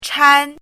chinese-voice - 汉字语音库
chan1.mp3